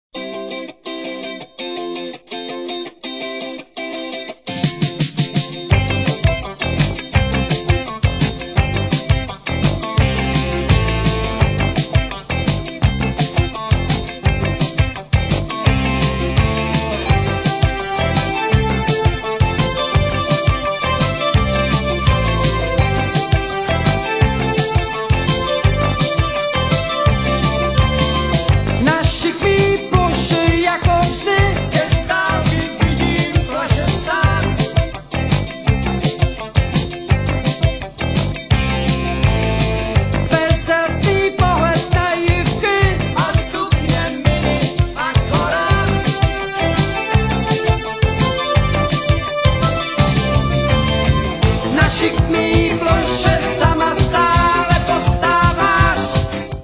doprovodný zpěv, kytary
baskytara
perkuse, bicí
saxofony, doprovodný zpěv, klávesové nástr
housle